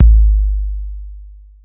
Waka KICK Edited (74).wav